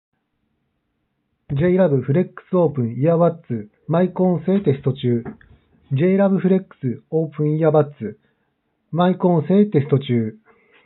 マイク性能は下の上
少しこもり気味で解析度が悪い。
✅静かな環境（40dB前後）